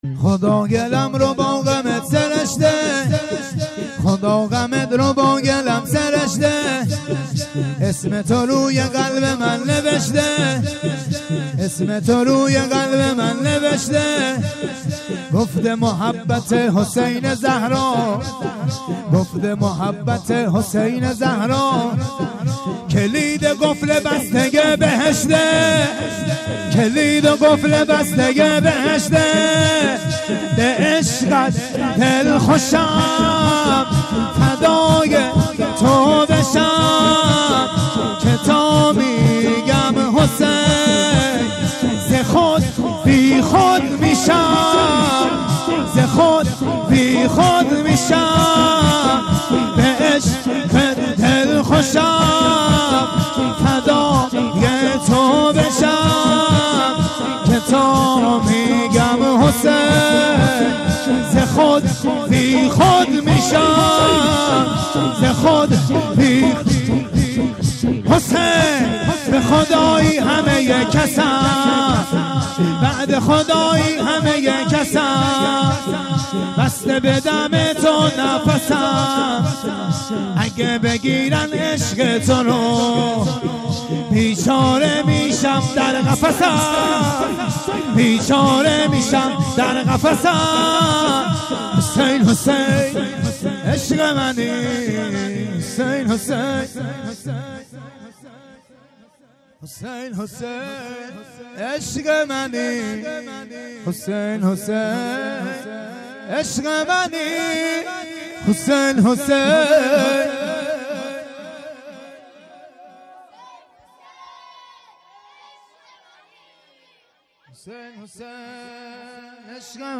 دانلود فایل های صوتی جشن میلاد حضرت زینب